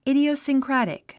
発音 ìdiousiŋkrǽtik イディオゥスィンクラティック